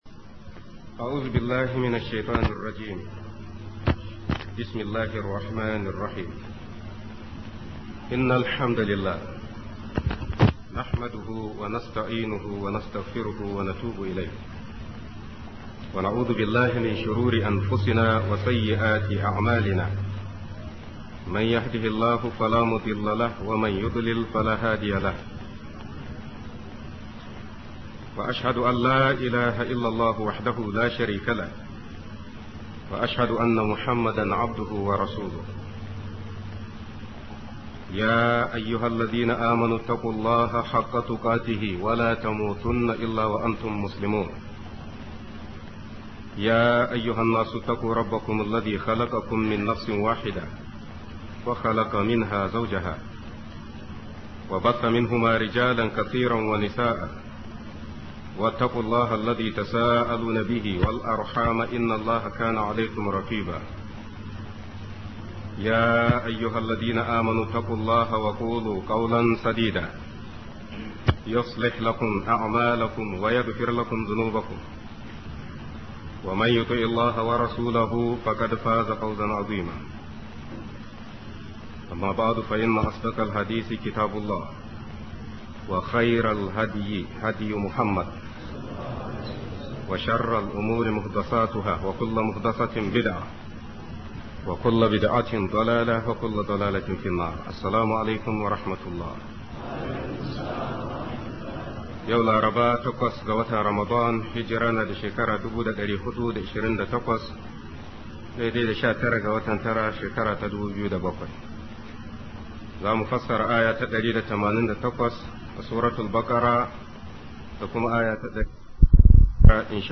HUKUNCIN CIN HARAM - MUHADARA